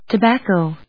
音節to・bac・co 発音記号・読み方
/təbˈækoʊ(米国英語), tʌˈbæˌkəʊ(英国英語)/